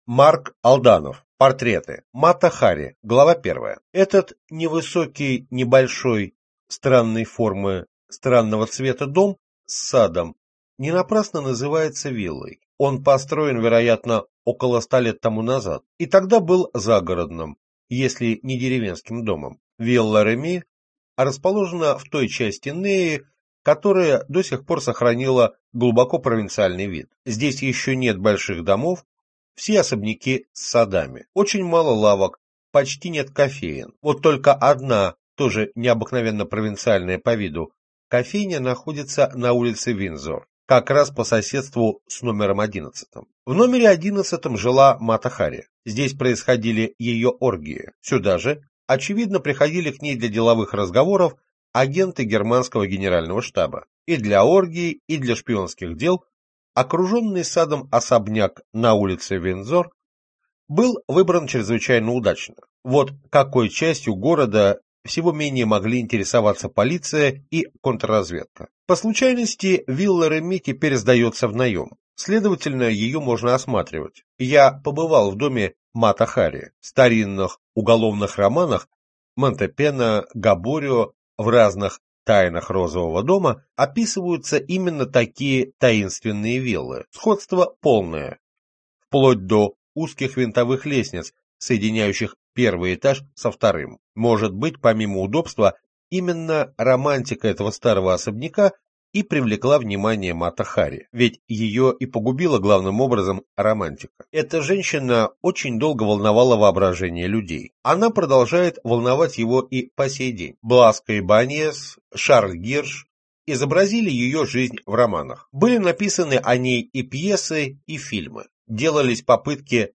Аудиокнига Шпионские тайны ХХ века. Мата Хари, Азеф, Лоуренс | Библиотека аудиокниг